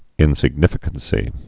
(ĭnsĭg-nĭfĭ-kən-sē)